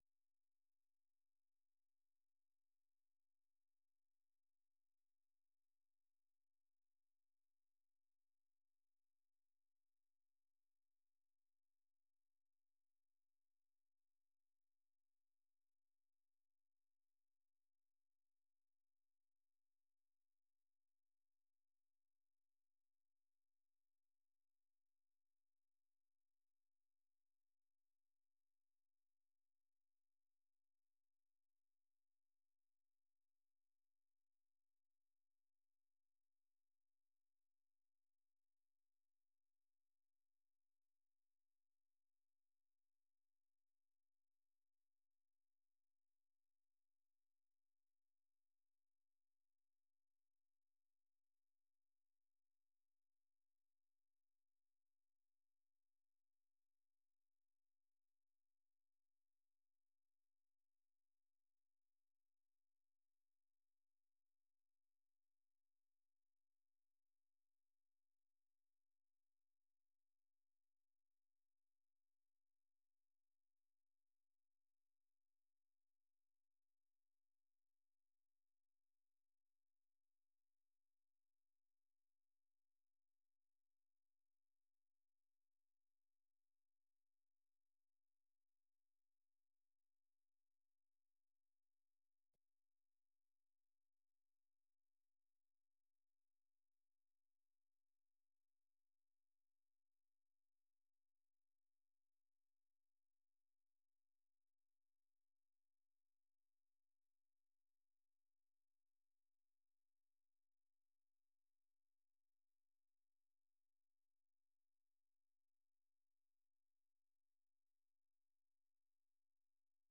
생방송 여기는 워싱턴입니다 아침